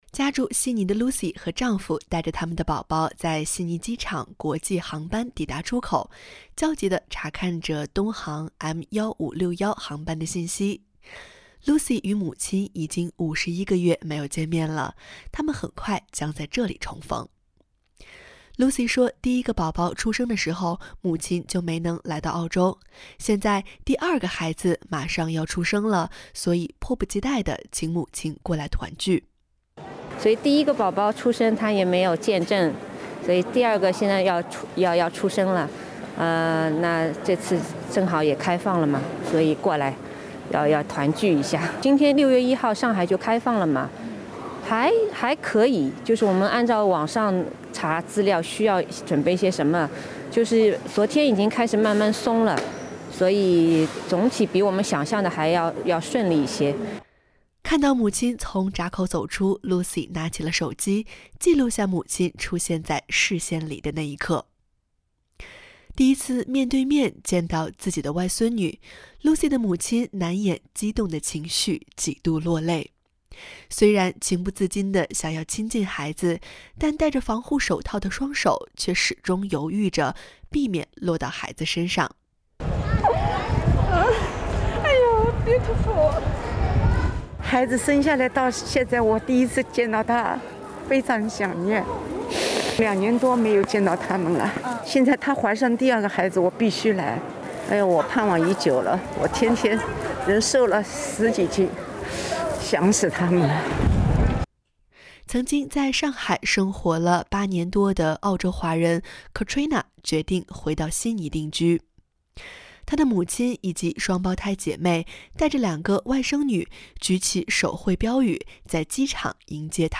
从疫情封控下的中国走出来的人们和前来接机的亲友们，面对SBS中文新闻的镜头诉说着各自的艰难和欣喜。